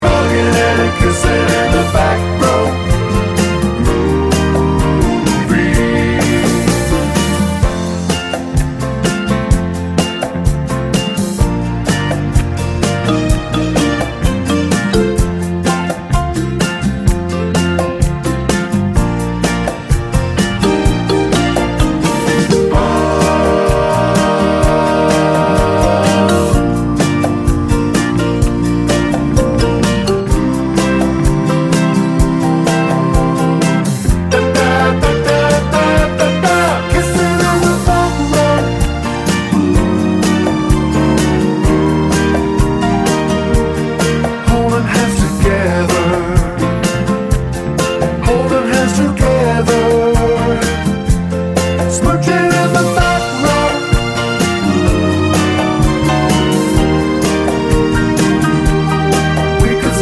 Two Semitones Down Soul / Motown 7:08 Buy £1.50